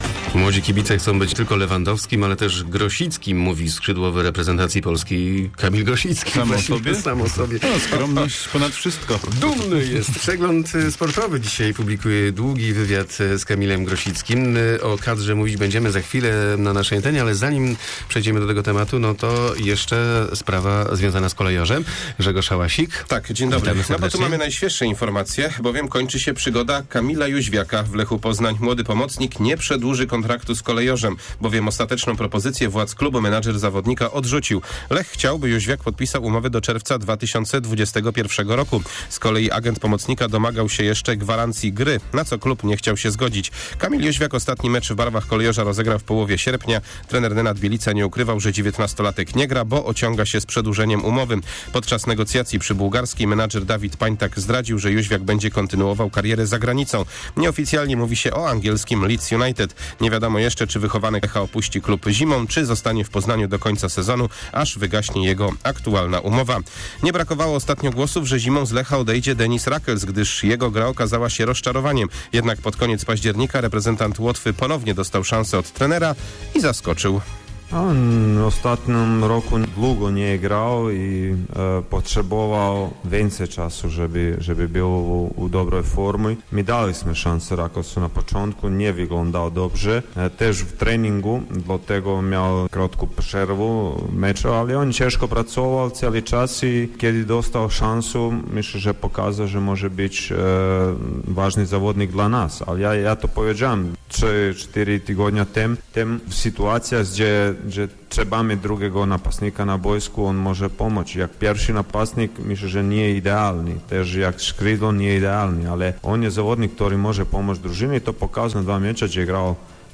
08.11 serwis sportowy godz. 7:45